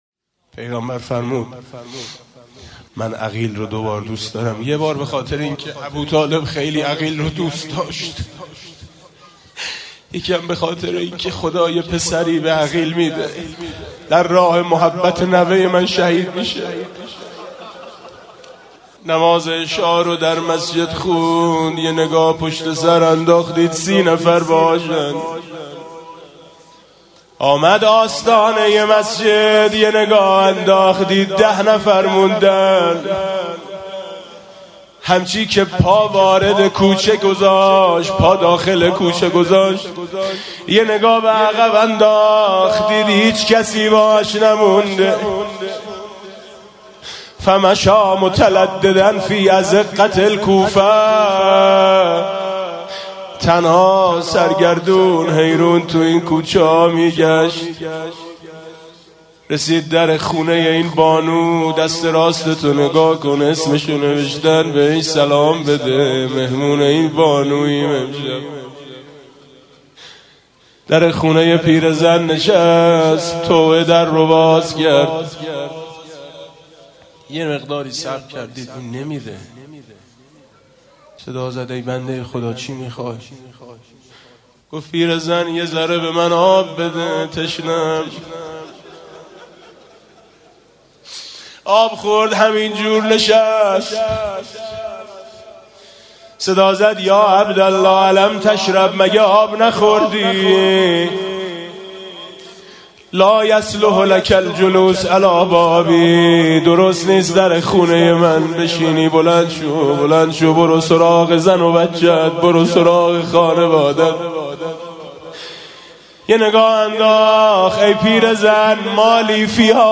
اجرا شده در: شب‌های ماه محرم ۱۴۰۳
هیأت آیین حسینی [مجتمع امام رضا (علیه‌السلام)]